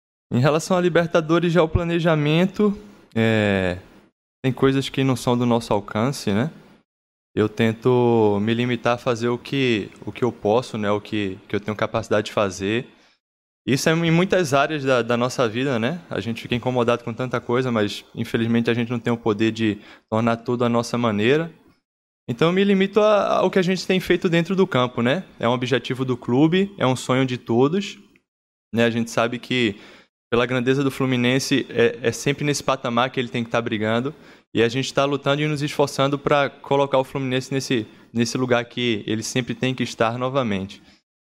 O zagueiro Nino que voltou a ser titular da zaga do Fluminense na partida contra o Botafogo foi o escolhido para a entrevista desta quarta-feira (27.01) e entre vários assuntos que respondeu das perguntas dos jornalistas, ele contestou as críticas que estão sendo feitas ao time tricolor que não tem jogado bem nas últimas partidas.
Acompanhe aqui outros temas da coletiva: